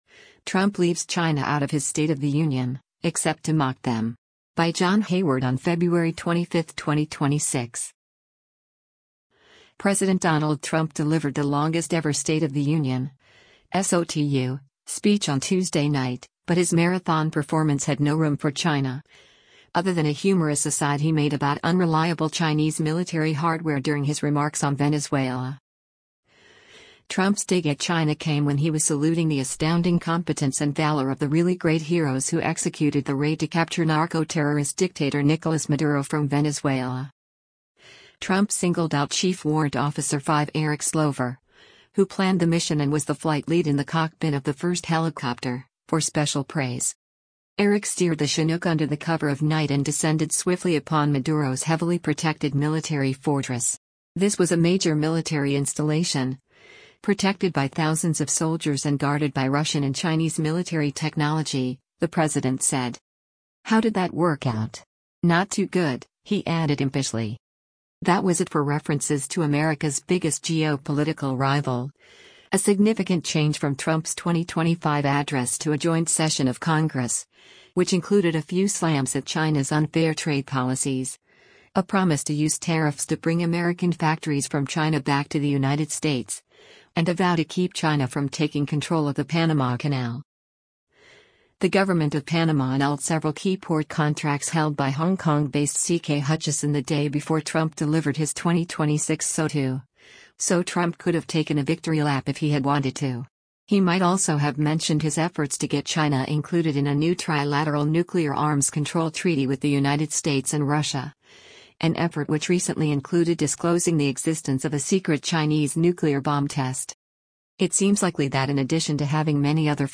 President Donald Trump delivers the State of the Union address to a joint session of Congr